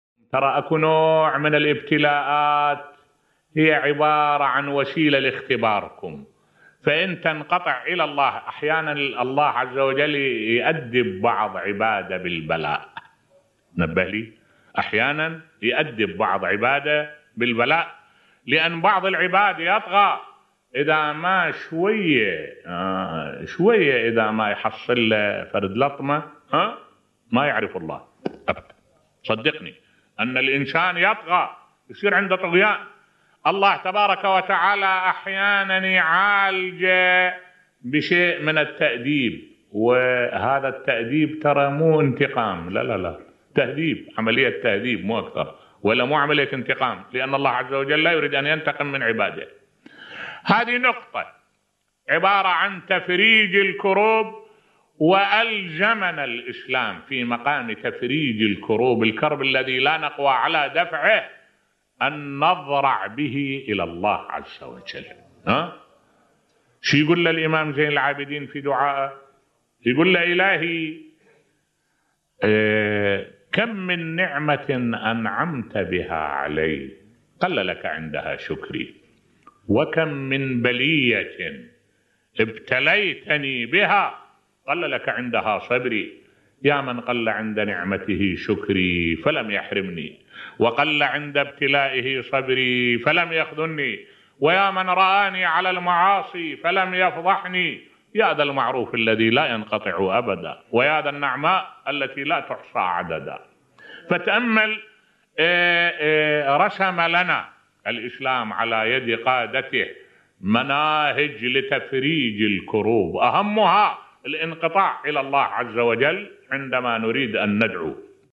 ملف صوتی احيانا الله تعالى يأدب بعض عبادة بالبلاء بصوت الشيخ الدكتور أحمد الوائلي